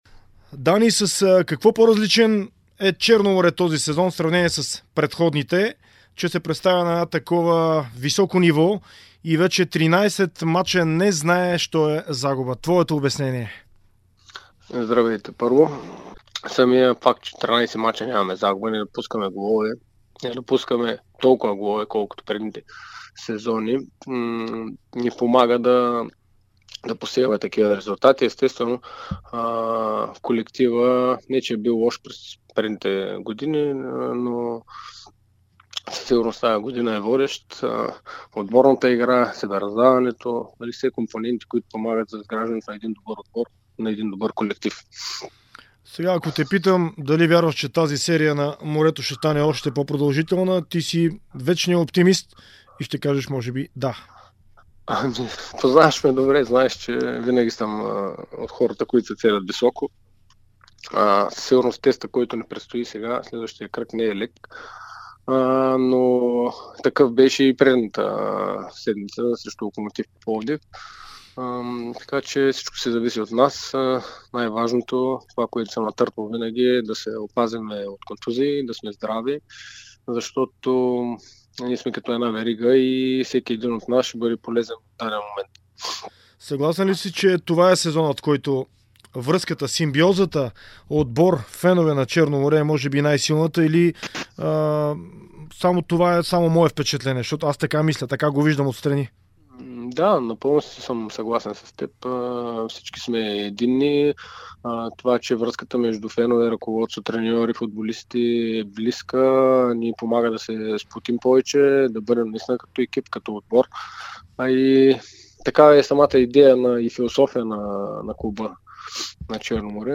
Капитанът на Черно море Даниел Димов даде ексклузивно интервю пред Дарик радио и dsport, в което говори за силния старт на сезона, след 13 мача от който варненци още нямат загуба и се намират на второ място във временното класиране. Следващият мач на „моряците“ е сериозен тест за амбициите на отбора – гостуване срещу Левски на стадион „Георги Аспарухов“.